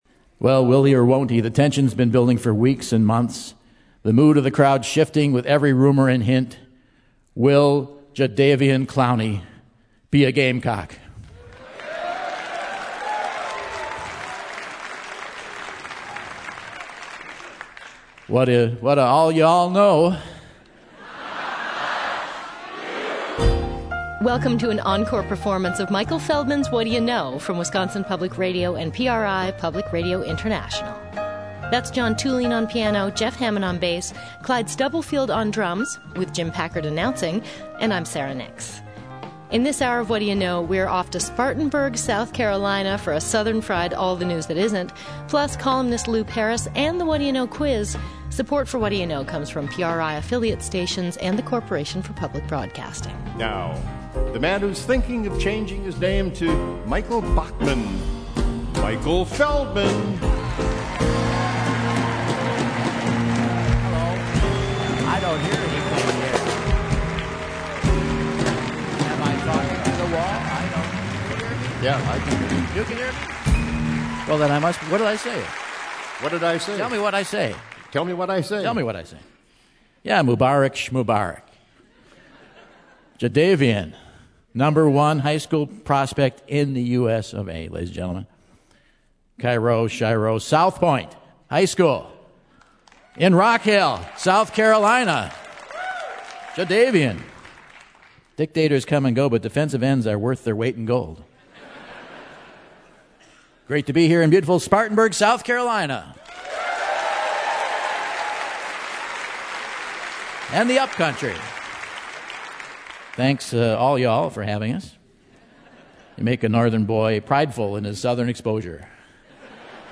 February 16, 2013 - Spartanburg, SC - Memorial Auditorium - Vintage Edition | Whad'ya Know?